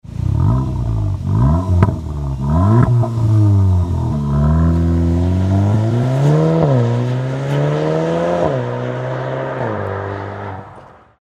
ECHAPPEMENT SPORT INOX A VALVES REMUS Cupra Ateca 2.0 TSI 300ch 4drive : LE SON SPORTIF
Un son profond et métallique caractéristique des faibles régimes moteur tandis que c'est une suite de plaisirs auditifs jusqu'au rupteur.
En valves ouvertes la sonorité de votre moteur s'exprime pleinement, en revanche quand les valves de votre Cupra Ateca 300ch 5FP (2018-2020) sont fermées le son est modéré pour une conduite plus discrète et un confort amélioré.
Seat_Cupra_Ateca_OPF_BJ19_REMUS_Axle-Back_Klappe_geöffnet.mp3